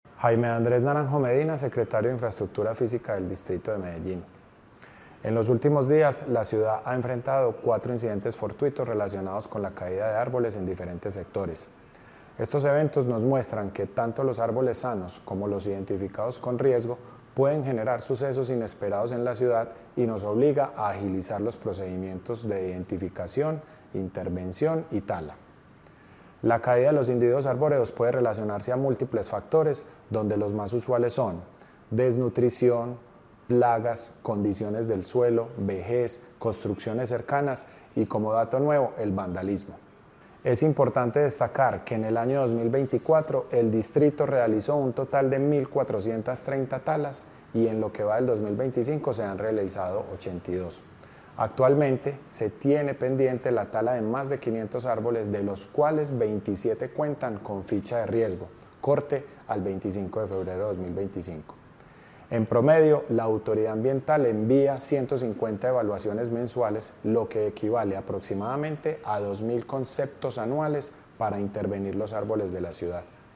Palabras de Jaime Andrés Naranjo Medina, secretario de Infraestructura Física